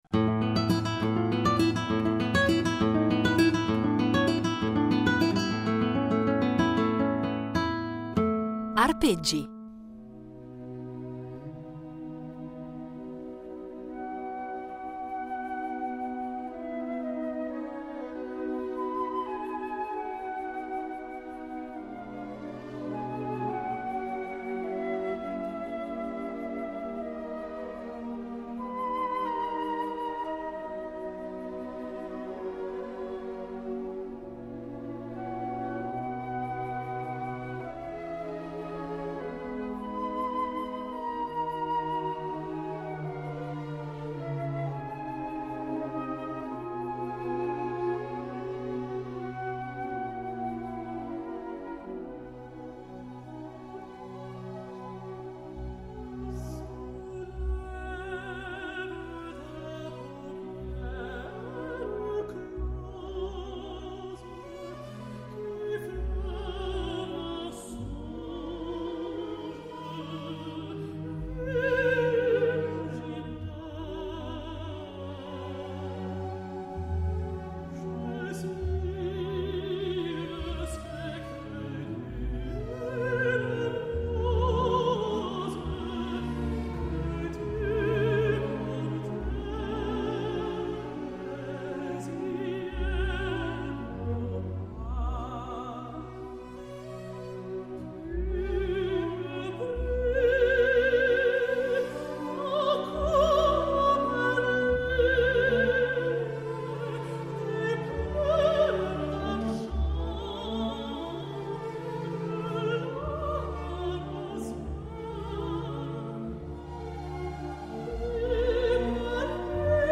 Insieme, andranno quindi ad impreziosire i nostri itinerari sonori con esecuzioni inedite di canzoni che hanno contribuito a rendere indimenticabili alcune pellicole cinematografiche.